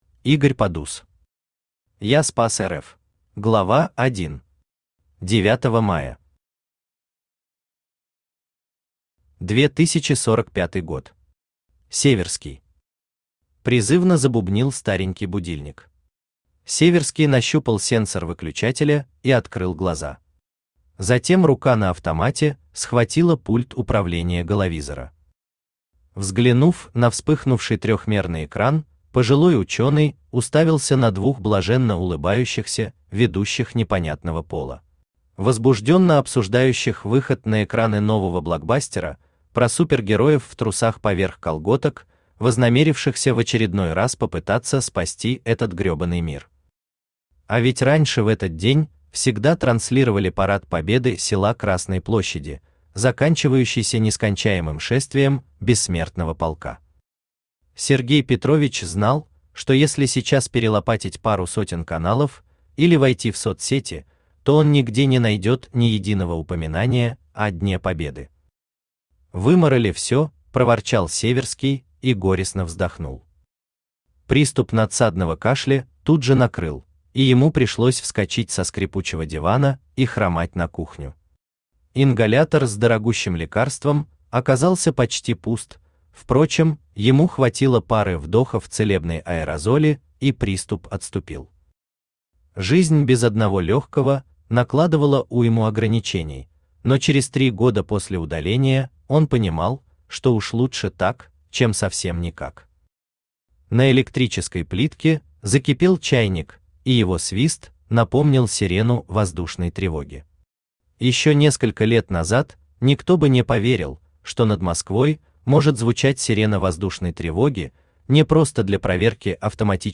Аудиокнига Я спас РФ | Библиотека аудиокниг
Aудиокнига Я спас РФ Автор Игорь Подус Читает аудиокнигу Авточтец ЛитРес.